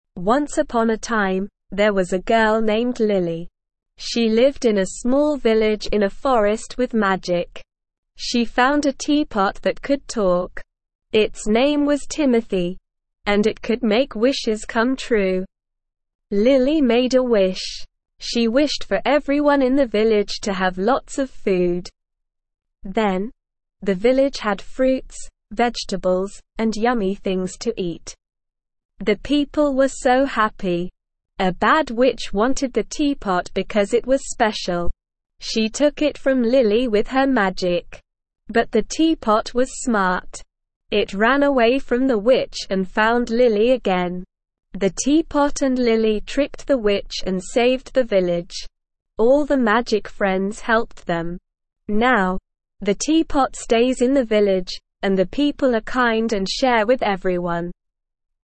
Slow
ESL-Short-Stories-for-Kids-Beginner-SLOW-Reading-The-Talking-Teapot.mp3